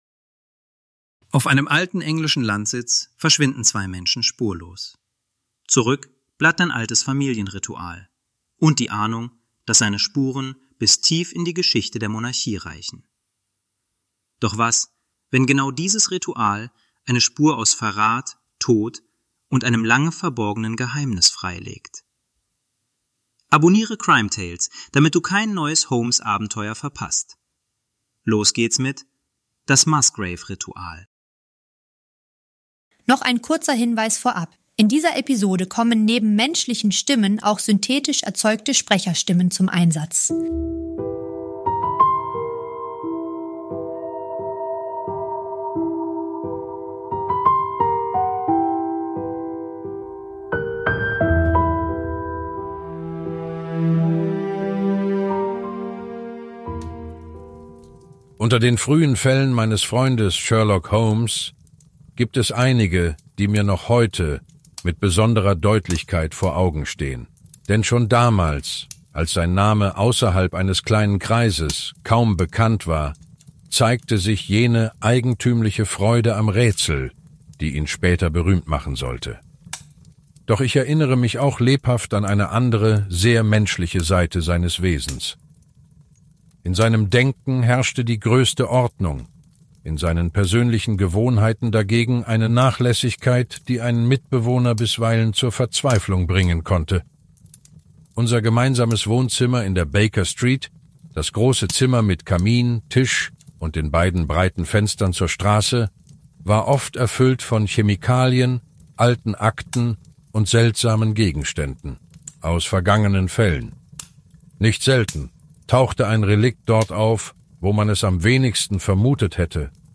- In dieser Produktion kommen neben unseren eigenen Stimmen auch synthetische Sprecherstimmen zum Einsatz.